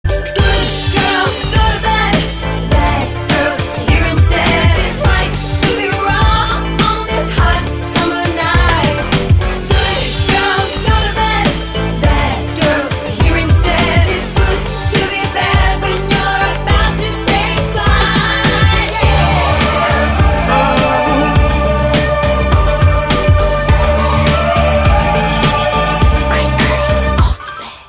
fusing elements of pop, rock, alternative & euro
diva"esque" anthem